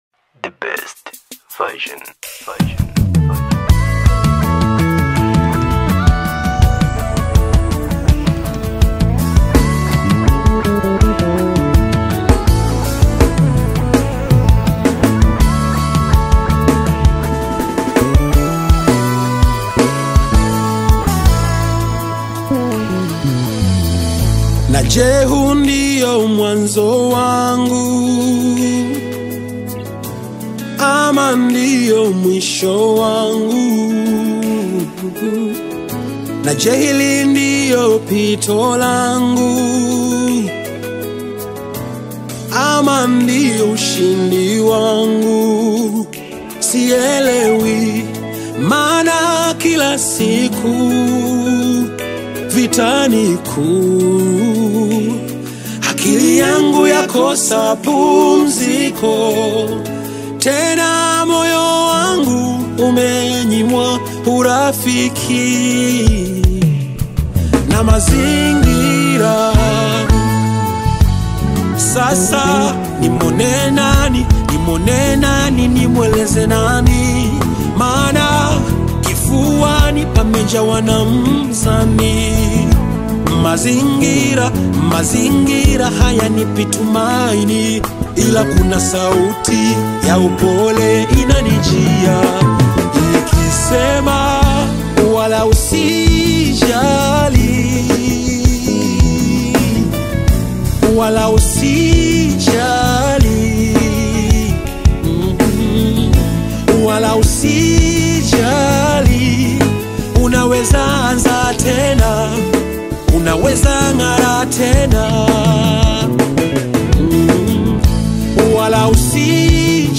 uplifting song
Known for his soulful voice and inspirational lyrics